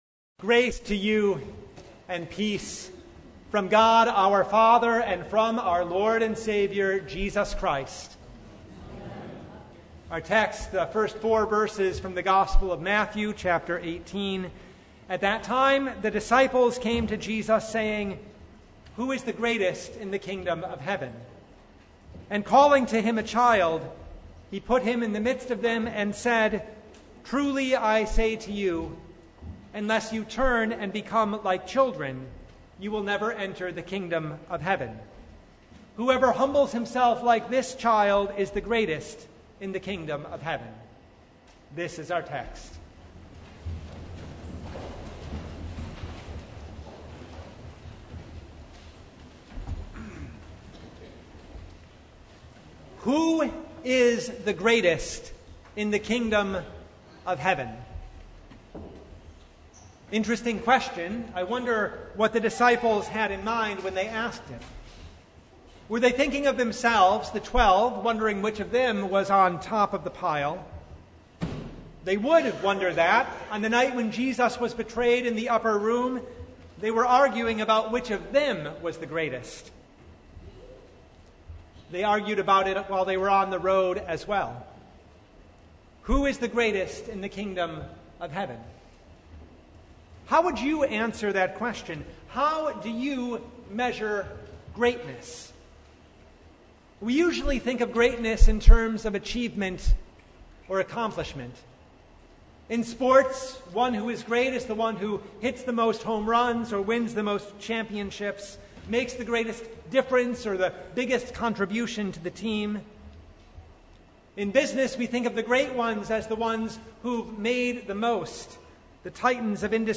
Sermon from Fifth Sunday in Martyrs’ Tide (2023)
Passage: Matthew 18:1-20 Service Type: Sunday of the Word